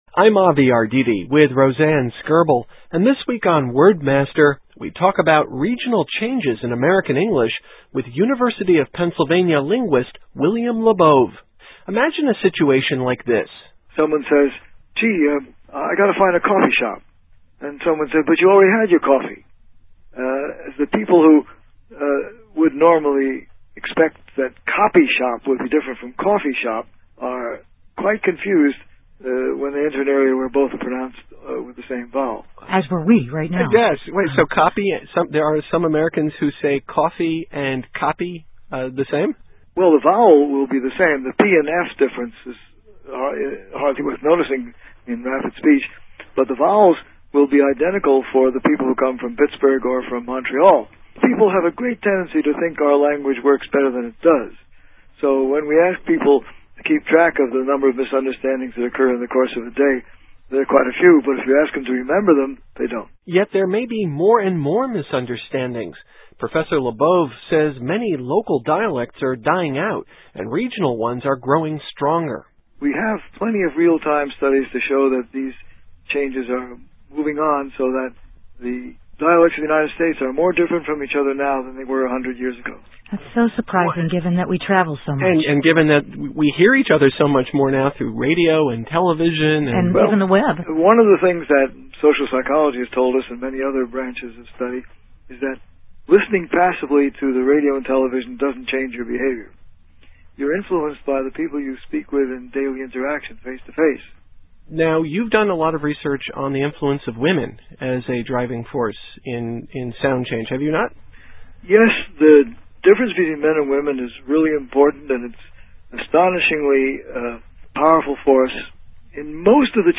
January 19, 2005 - Interview with William Labov: Sound Change, Part 2